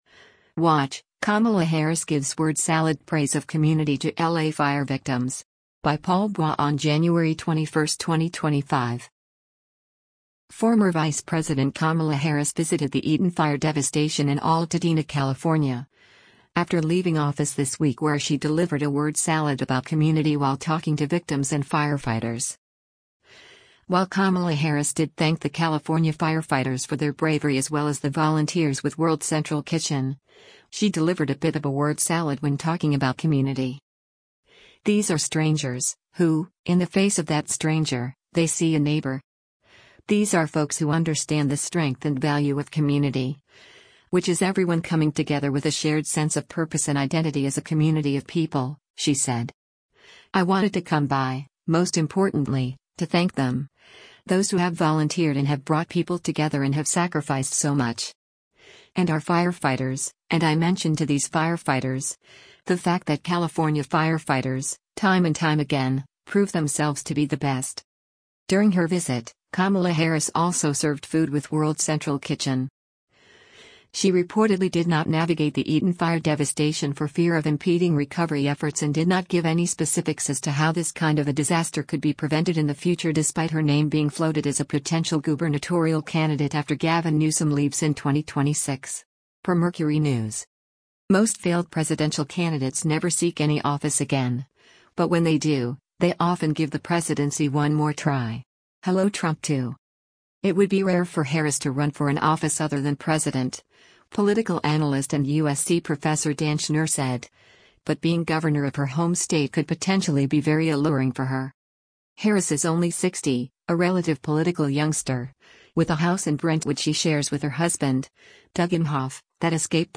Former Vice President Kamala Harris visited the Eaton fire devastation in Altadena, California, after leaving office this week where she delivered a word salad about “community” while talking to victims and firefighters.